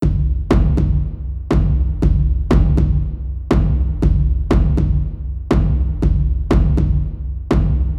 bass-drum.wav